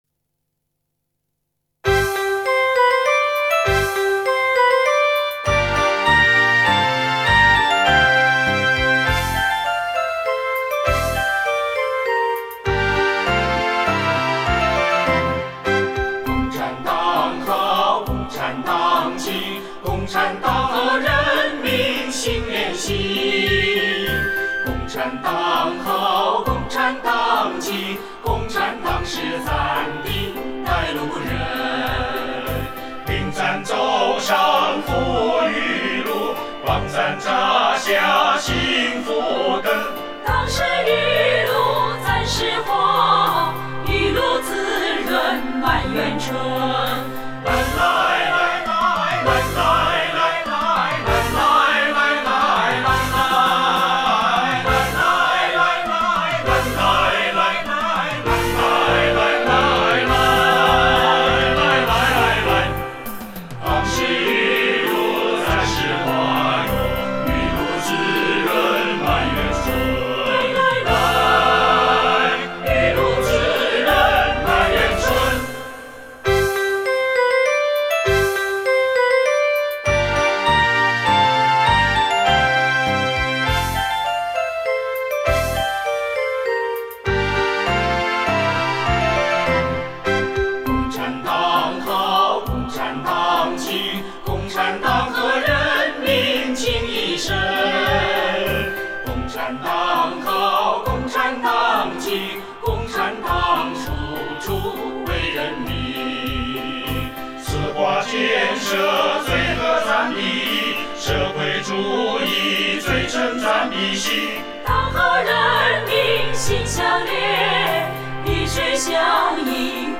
合唱